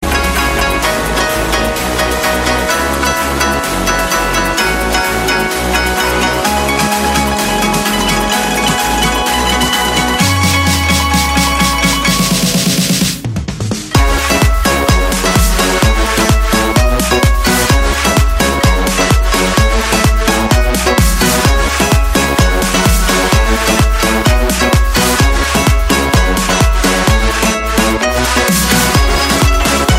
مكونة من 5 نغمات روميكس وبالة الماريمبا